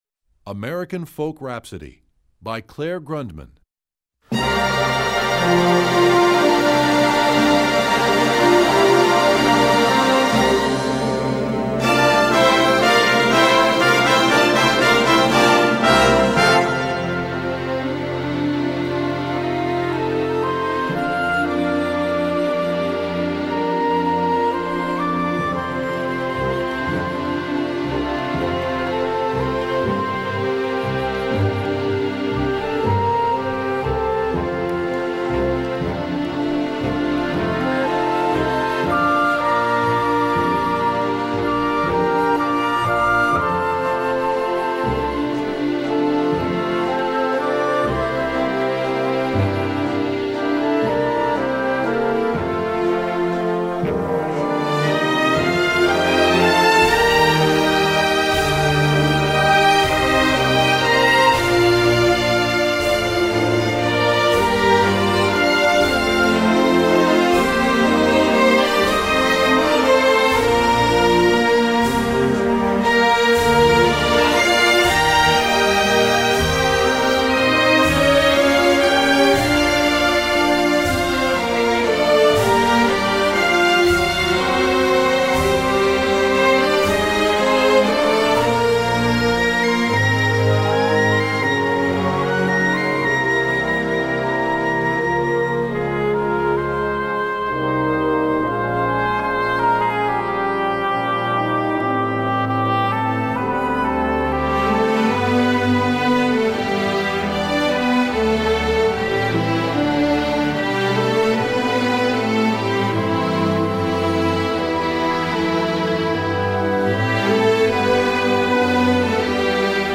Full Orchestra (MD)   Score